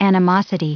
Prononciation du mot animosity en anglais (fichier audio)
Prononciation du mot : animosity